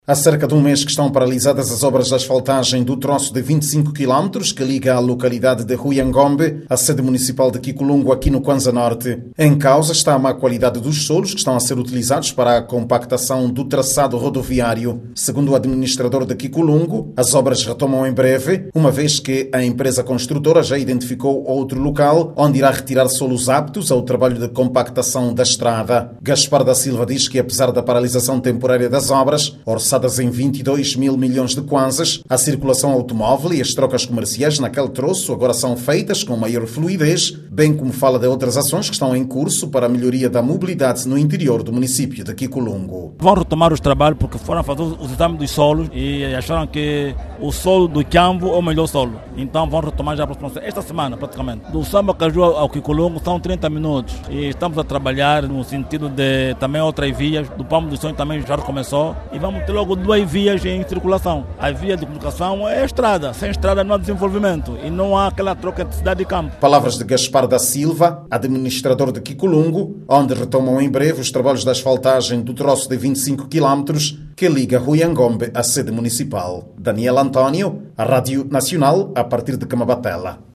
As obras de asfaltagem do troço de 25 quilómetros entre o Huiangombe e Quiculungo, na Província do Cuanza-Norte, paralisadas há mais de um mês, serão retomadas. Já estão ultrapassas os constrangimentos que impediram o normal andamento da empreitada. Clique no áudio abaixo e ouça a reportagem